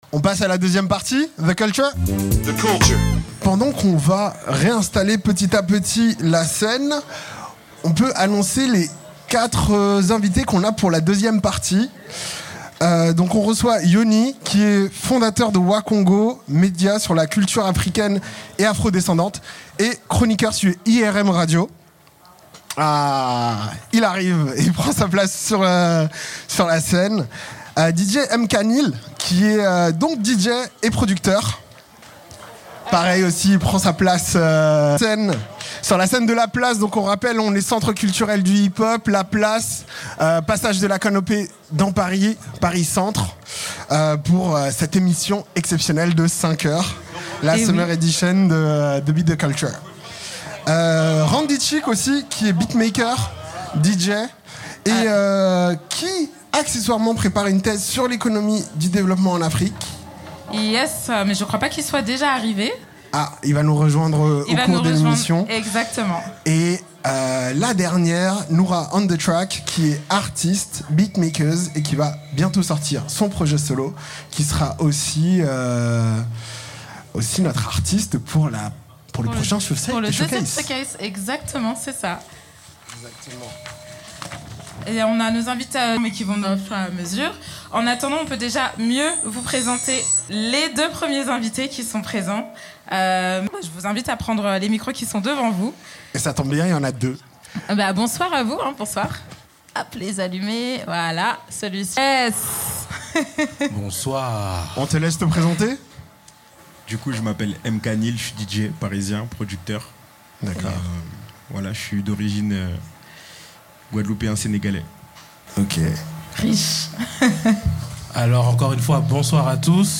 The Beat, The Culture fête l'été avec une édition spéciale de 5 heures en direct de La Place, centre culturel hip-hop !